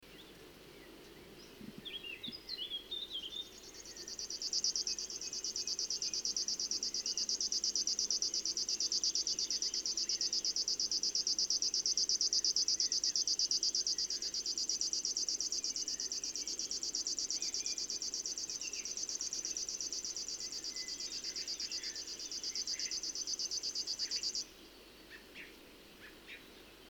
Locustelle fluviatile, Marche, 4 juin 2009, MP3, 421ko
une séquence de chant assez longue, elles peuvent plus courtes, séparées par un silence
L'oiseau est à 50 mêtres, les variations du niveau sonore sont peu importante,
l'oiseau ne tourne pas, ou très peu, la tête en chantant.